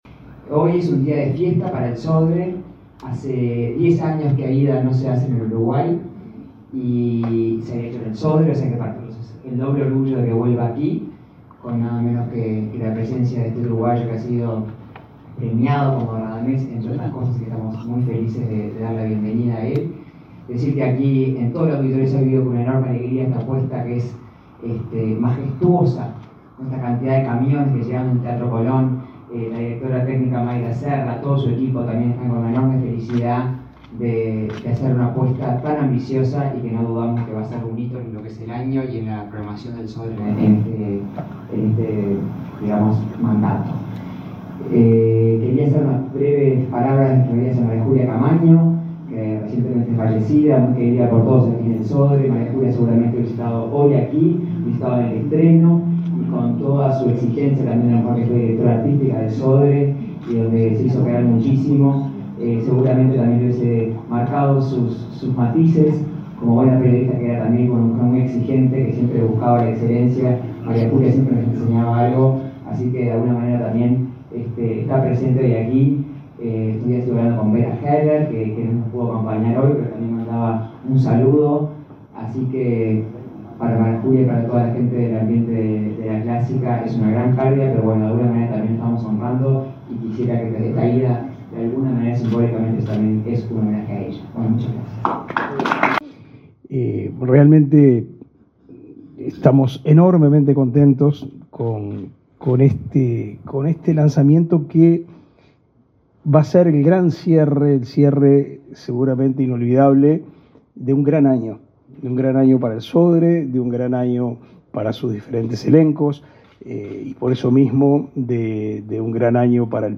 Palabra de autoridades en acto del Sodre
Palabra de autoridades en acto del Sodre 07/11/2023 Compartir Facebook X Copiar enlace WhatsApp LinkedIn La presidenta del Sodre, Adela Dubra, y el ministro Pablo da Silveira informaron a la prensa, este martes 7 en ese complejo cultural, acerca de la puesta en escena de la ópera Aída.